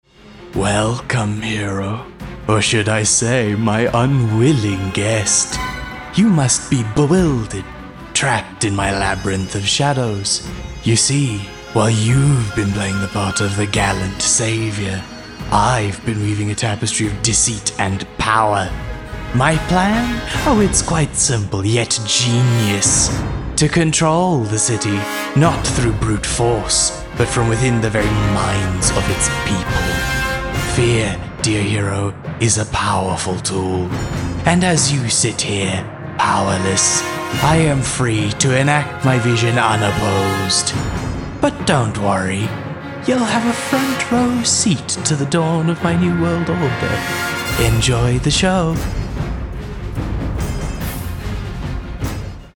animation, articulate, authoritative, character, Deep
Character- Villain Monologue